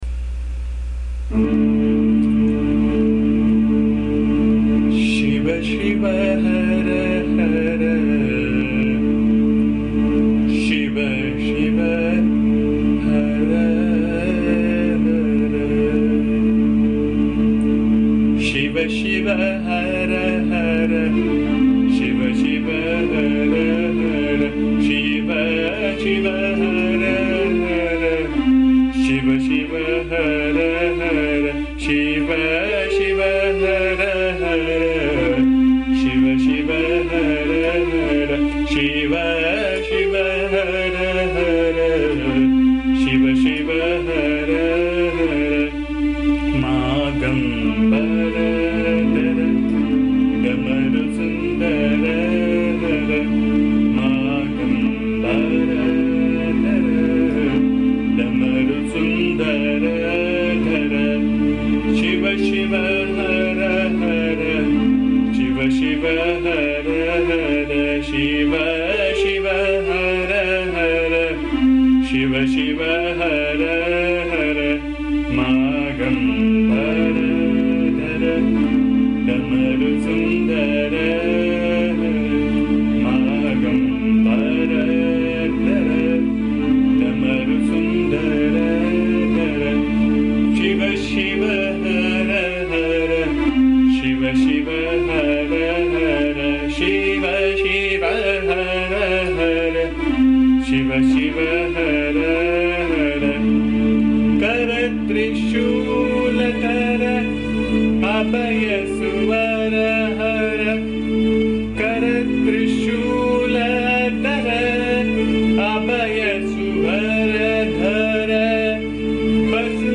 This is a very simple bhajan praising Siva.
The song has been recorded in my voice which can be found here. Please bear the noise, disturbance and awful singing as am not a singer.